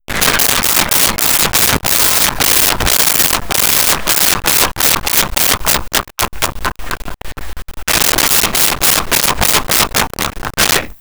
Laughing Male 02
Laughing Male 02.wav